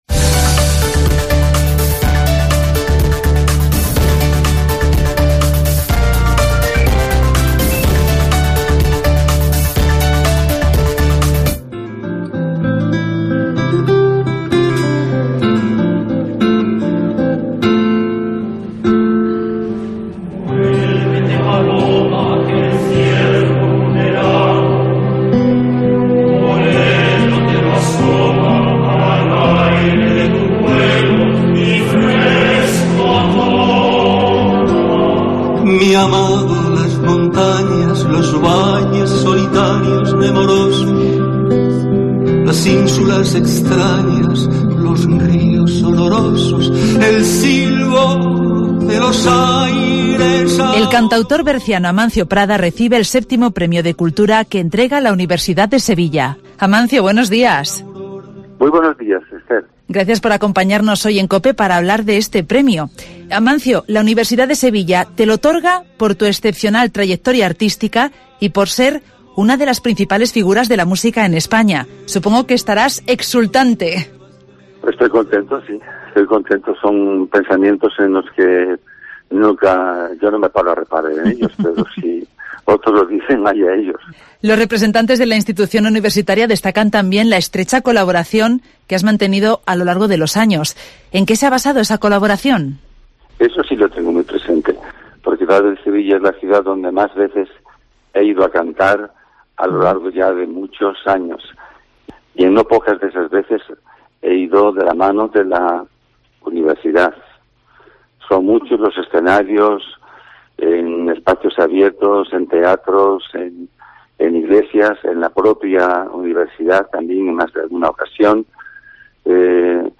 Entrevista a Amancio Prada tras recibir el VII Premio de Cultura de la Universidad de Sevilla